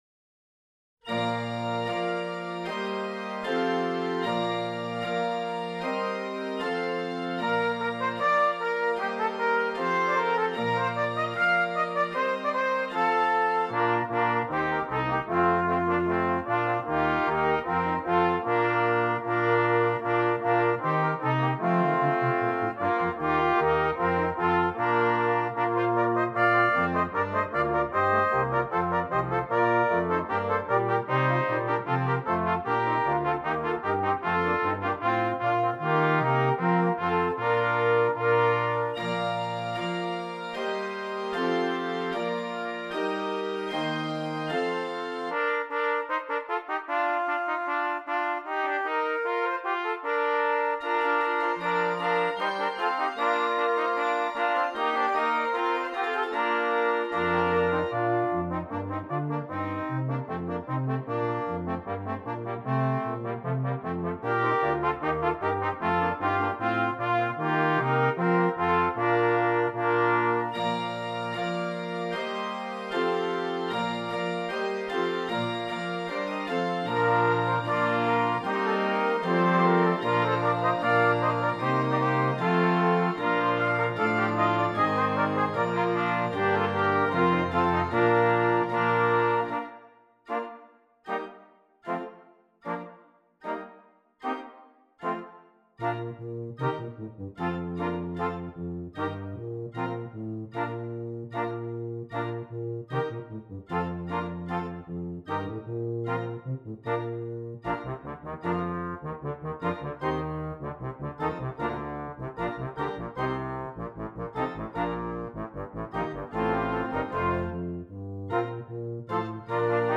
Christmas
Brass Quintet and Organ
Traditional Carol